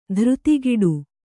♪ dhřtigiḍu